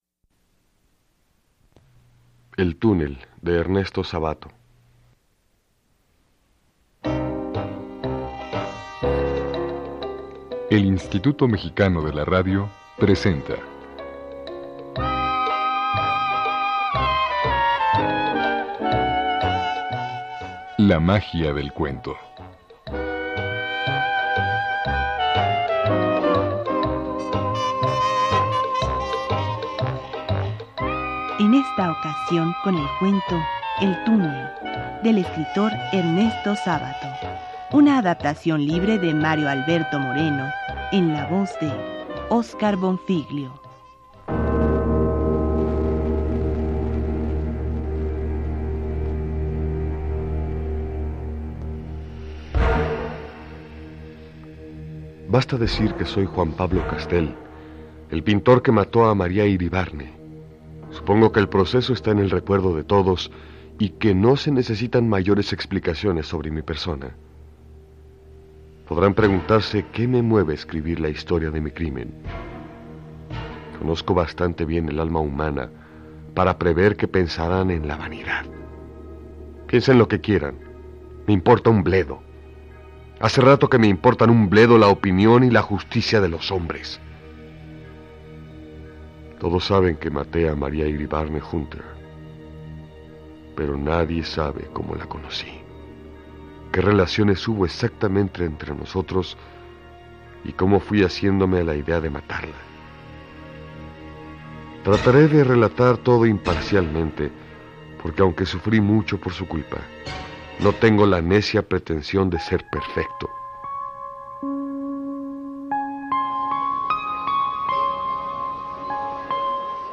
narrar el cuento “El túnel” de Ernesto Sabato en el programa “La magia del cuento” el cual se transmitió en 1992.